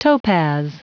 Prononciation du mot : topaz